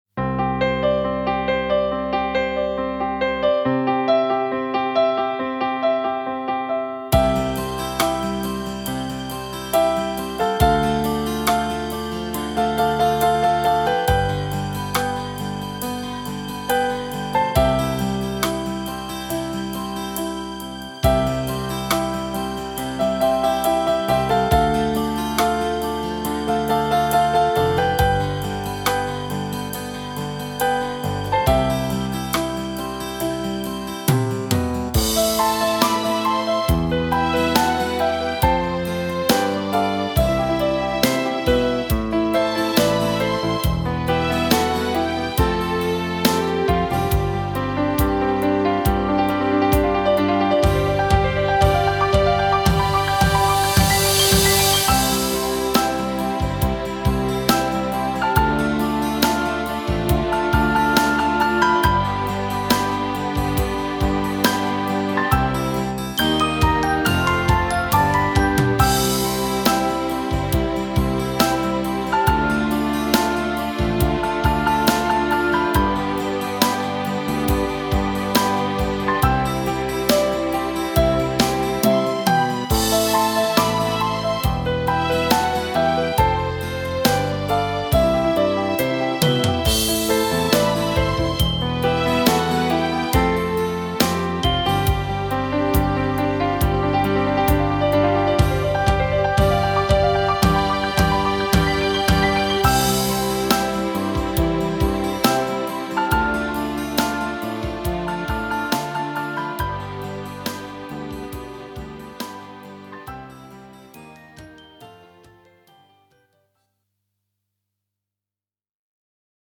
(INGEN former for overspil/trickindspilninger.)